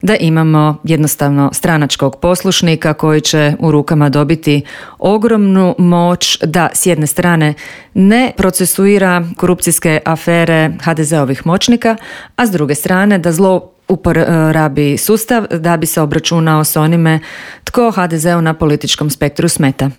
ZAGREB - Prijepori oko Ivana Turudića i procedure izbora glavnog državnog odvjetnika, izmjene Kaznenog zakona, veliki prosvjed oporbe, sindikalni prosvjedi i potencijalni štrajkovi neke su od tema o kojima smo u Intervjuu Media servisa razgovarali sa saborskom zastupnicom iz Stranke s imenom i prezimenom Dalijom Orešković.